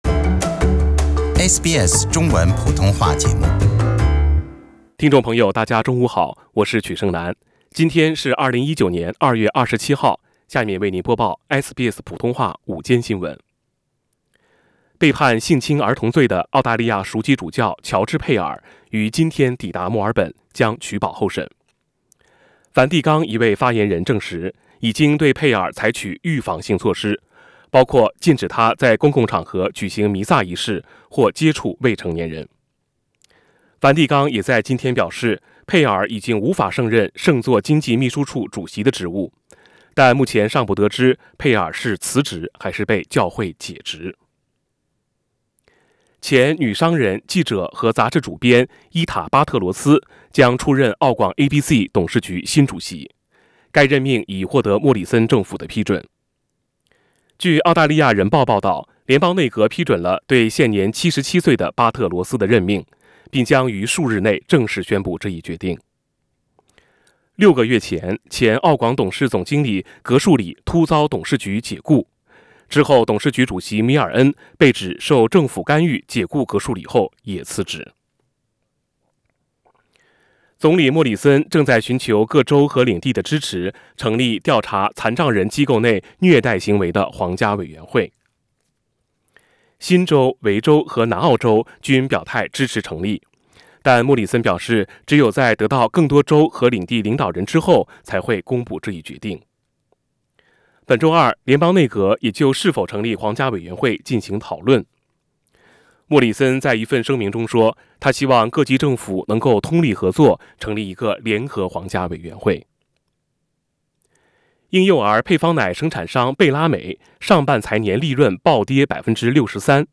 【SBS午間新聞】2月27日